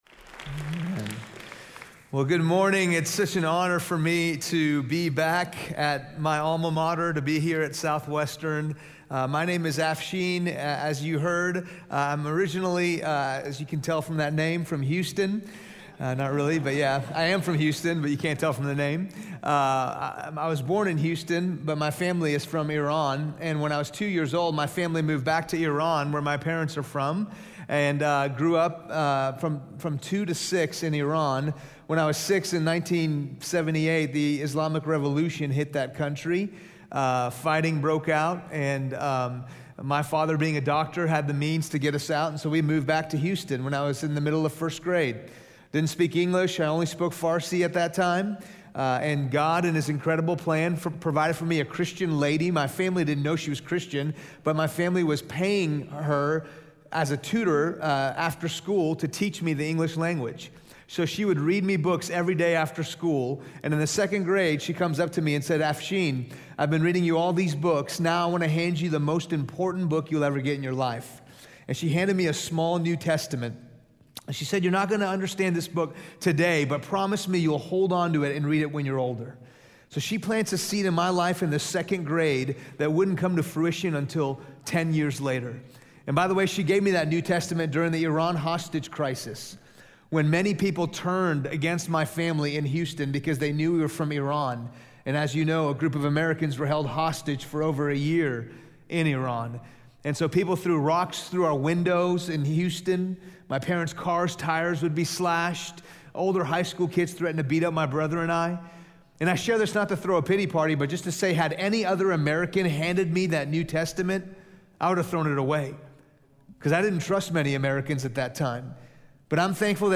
in SWBTS Chapel on Thursday March 9, 2017